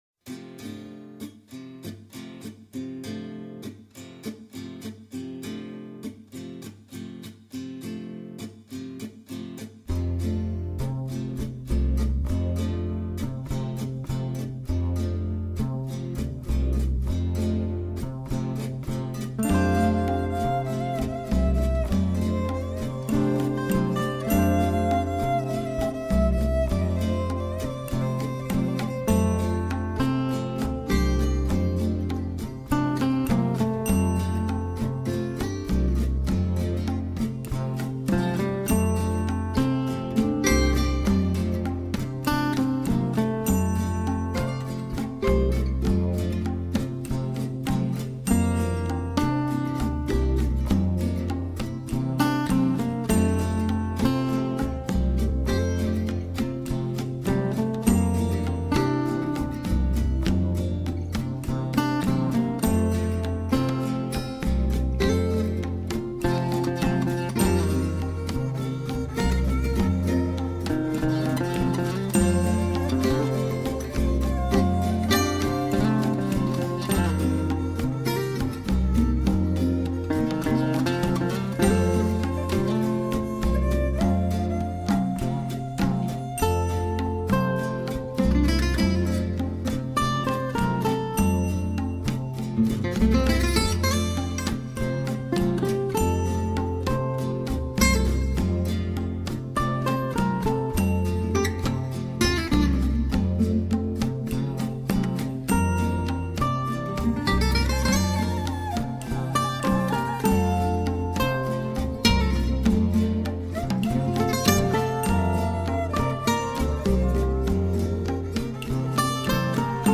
seconde guitare
basse, batterie et percussions.